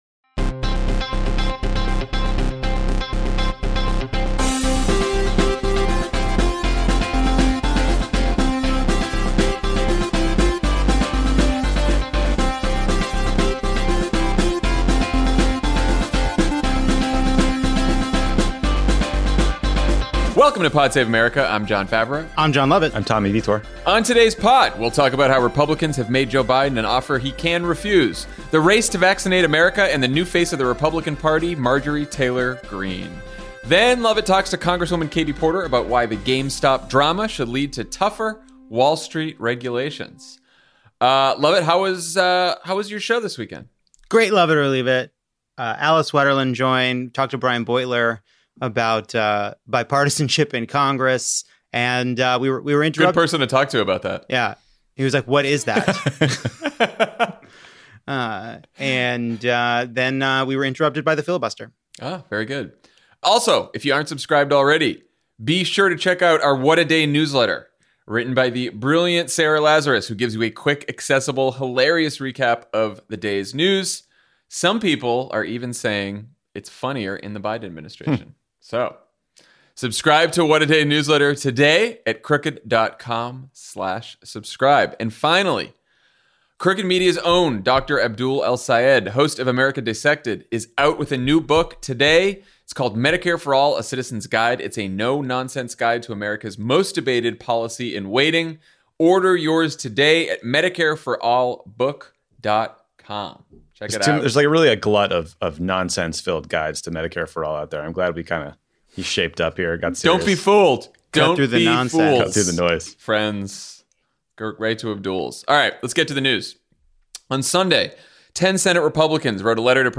Republicans make Joe Biden an offer he can refuse, the race to vaccinate America is on, and the new face of the GOP is a QAnon school shooting truther who believes that wildfires are caused by Jewish laser beams. Then Congresswoman Katie Porter talks to Jon Lovett about getting people access to mental health care during the pandemic and why the GameStop drama should lead to tougher Wall Street regulation.